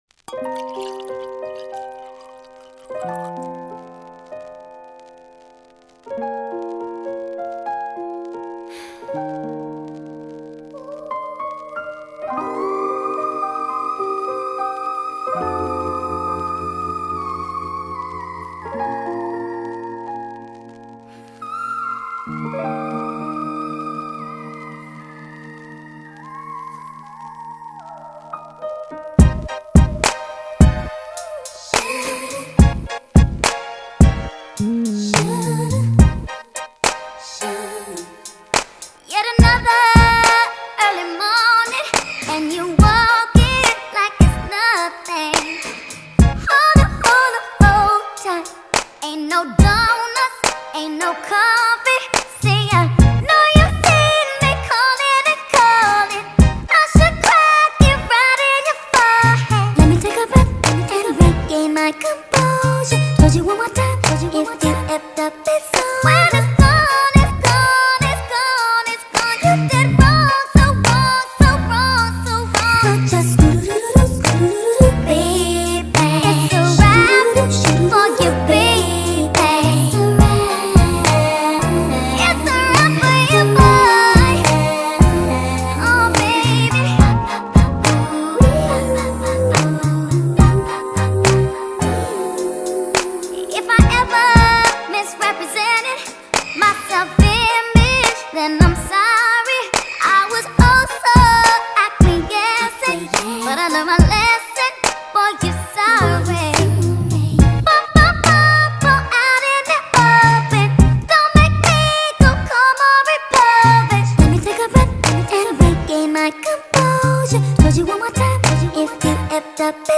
sped up remix
TikTok remix